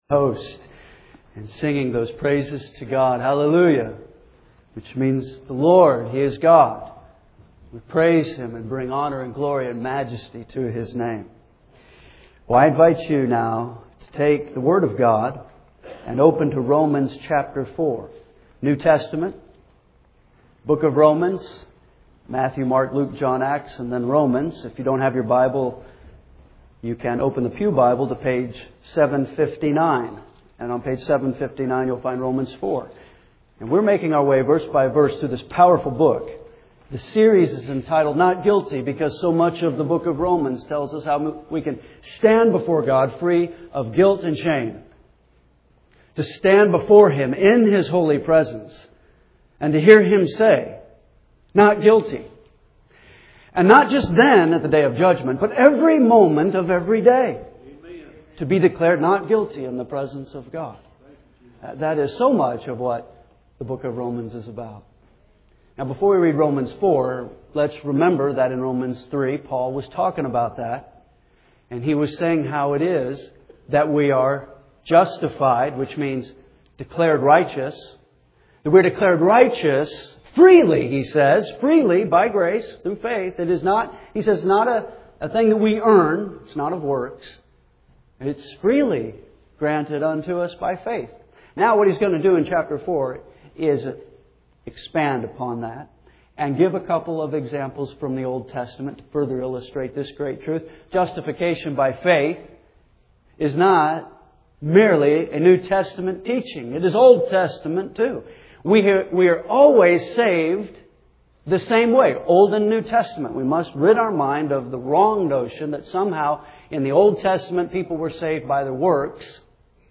Our series of messages is entitled, “Not Guilty,” largely because so much of this book tells us how we may stand before God free of guilt and condemnation.